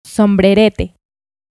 Sombrerete (Spanish: [sombɾeˈɾete]